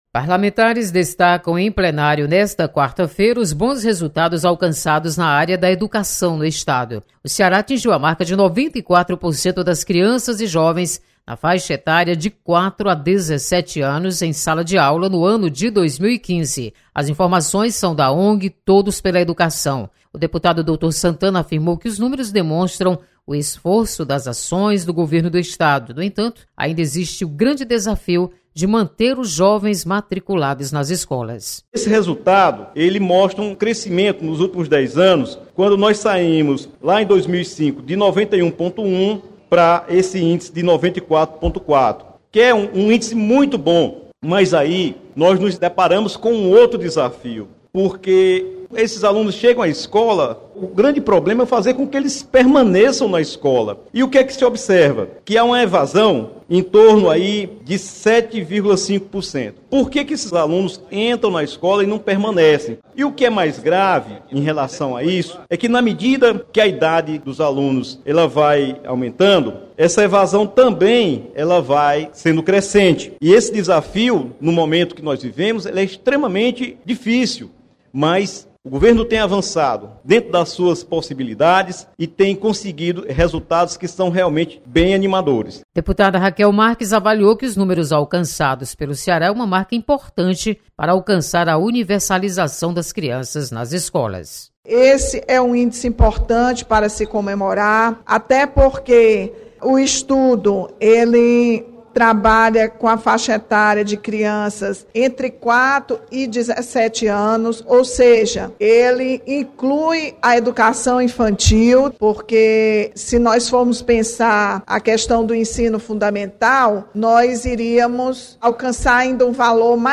Deputada Rachel Marques e deputado doutor Santana comemoram resultados positivos da educação.